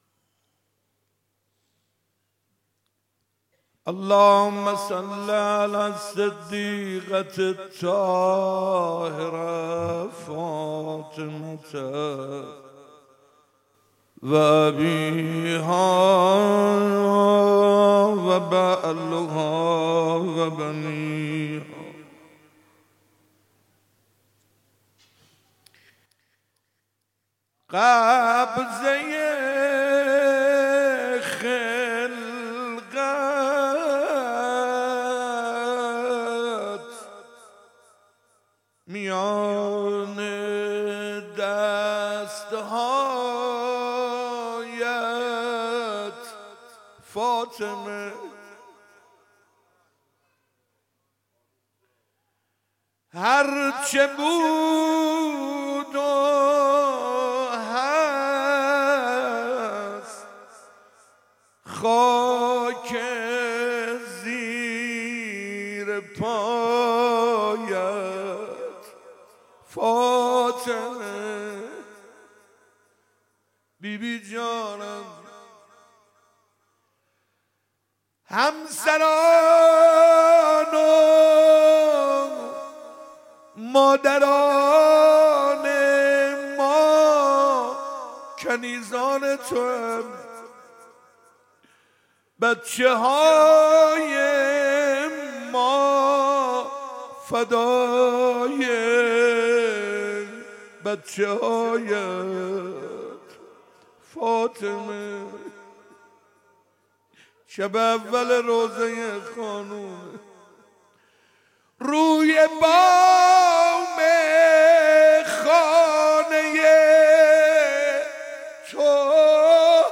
25 دی 97 - مسجد ارک - روضه - هرچه بود و هست خاک زیر پایت فاطمه